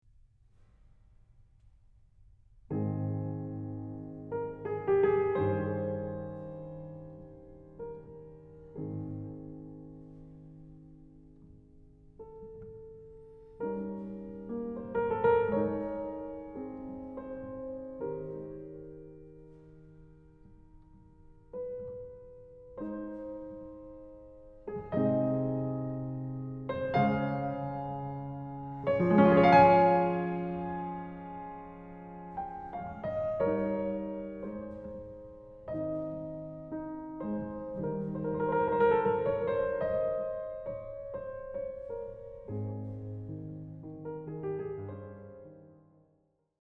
They are all in a Major key.